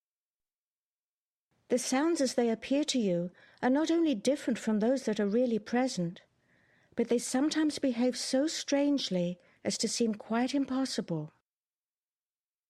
این توهم با تکرار بی وقفه یک جمله معمولی بیان می‌شود اما آنچه شما در نهایت می‌شنوید برخی اوقات کاملا عجیب به گوش می‌رسند.
در مرحله بعد بخش کوچکی از این جمله (مثلا فقط چند کلمه از آن) چندین بار تکرار می شود.